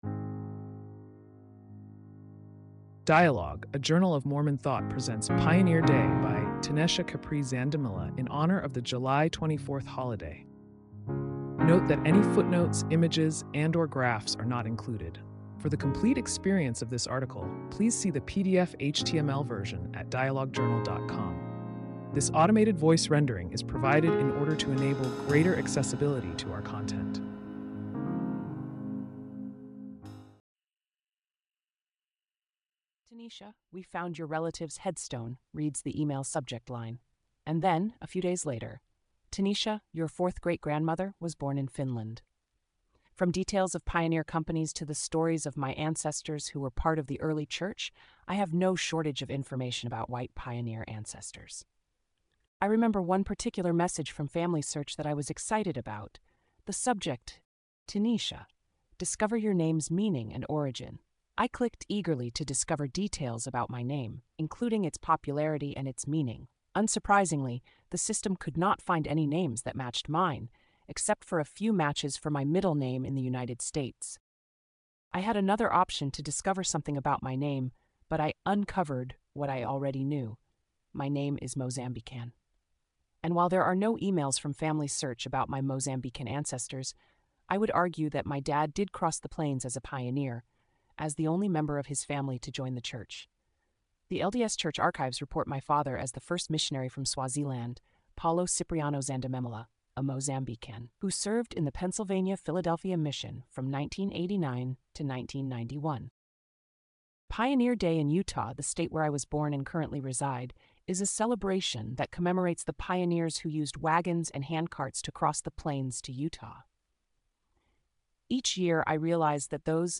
This automated voice rendering is provided in order to enable greater accessibility to our content.